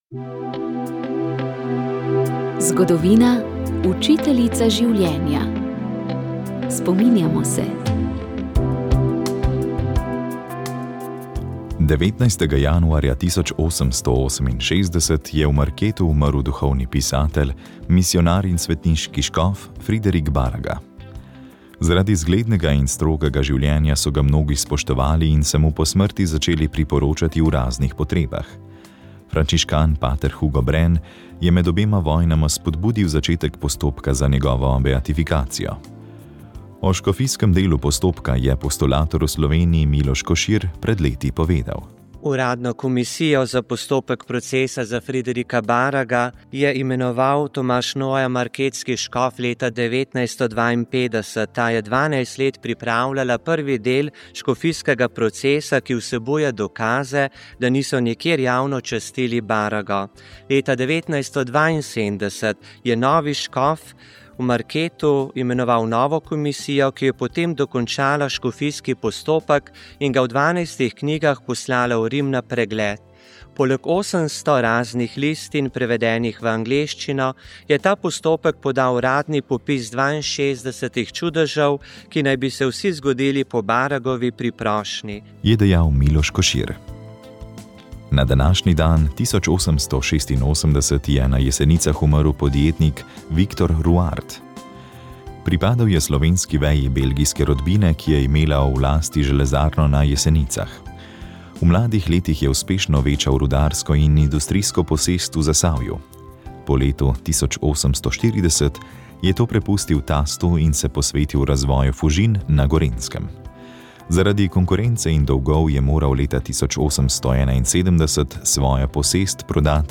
Rožni venec
Molili so radijski sodelavci.